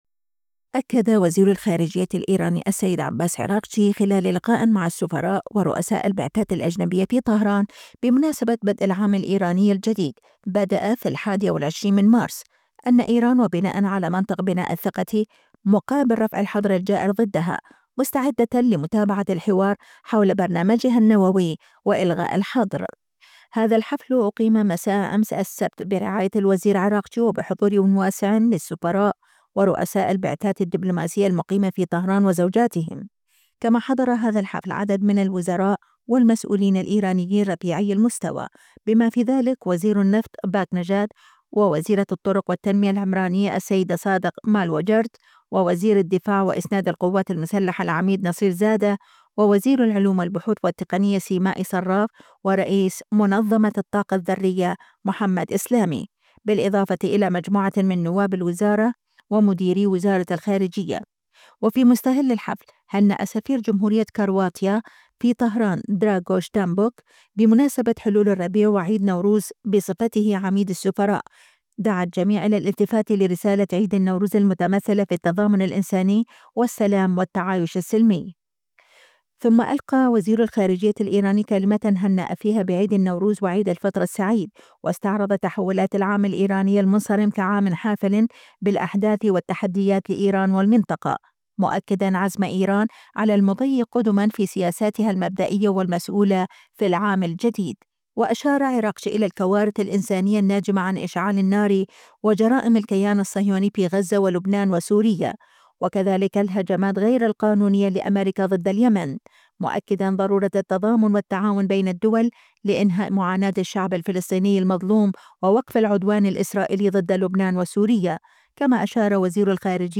أكد وزير الخارجية الإيراني " السيد عباس عراقجي" خلال لقاء مع السفراء ورؤساء البعثات الأجنبية في طهران بمناسبة بدء العام الايراني الجديد (بدأ في 21 مارس): ان ايران وبناءً على منطق بناء الثقة مقابل رفع الحظر الجائر ضدها، مستعدة لمتابعة الحوار حول برنامجها النووي والغاء الحظر.
ثم ألقى وزير الخارجية الإيراني كلمة هنّأ فيها بعيد النوروز وعيد الفطر السعيد، واستعرض تحولات العام الايراني المنصرم كعام حافل بالأحداث والتحديات لإيران والمنطقة، مؤكدًا عزم ايران على المضي قدمًا في سياساتها المبدئية والمسؤولة في العام الجديد.